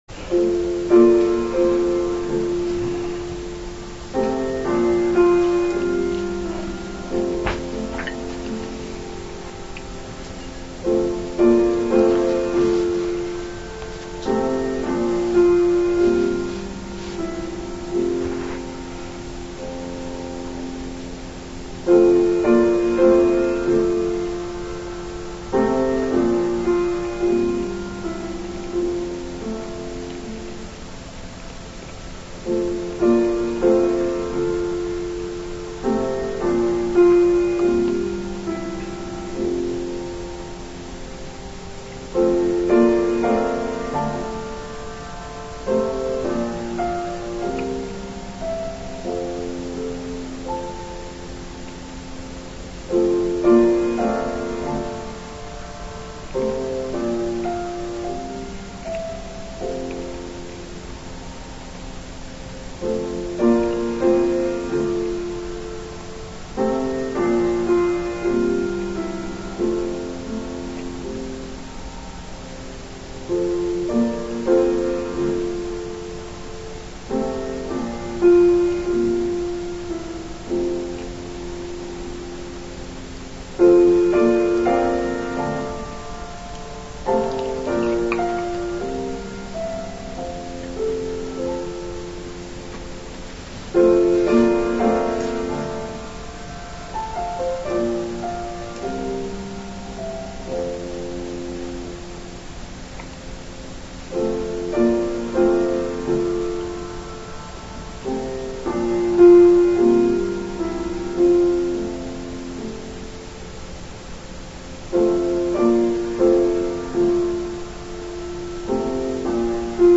Musical Prelude: I will play some gentle, reflective music to centre myself, before the start of the service.
On-Crime-and-Punishment-trimmed-Mono.mp3